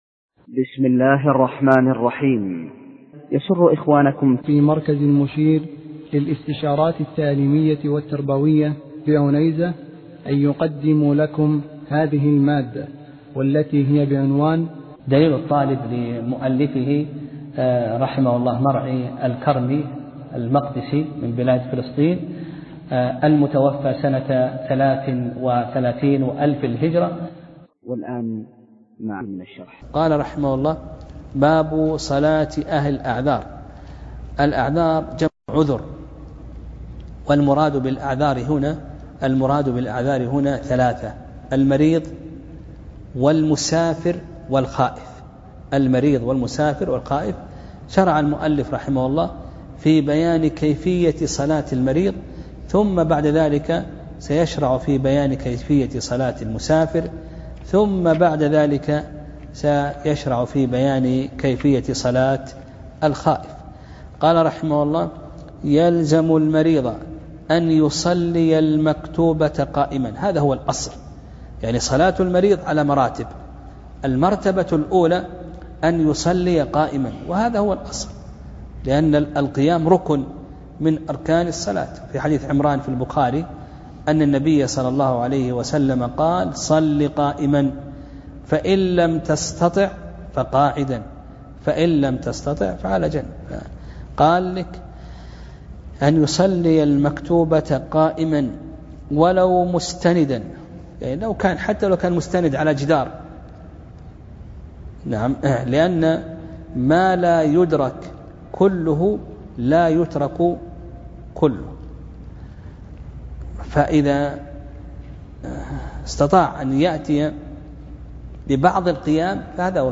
درس (14): باب صلاة أهل الأعذار